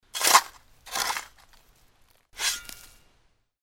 shovel5.mp3